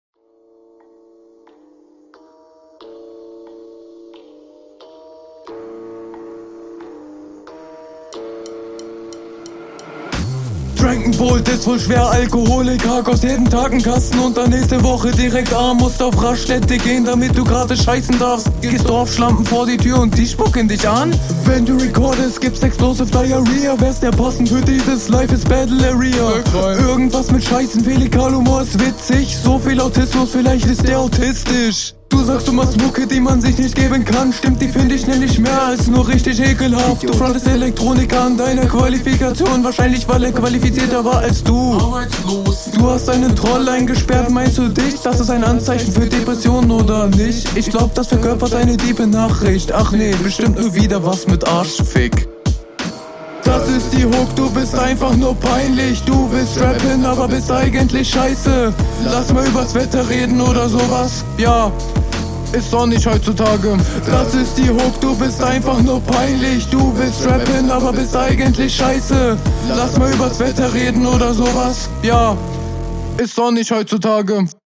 Yo du drückst deine Stimme leider zu sehr.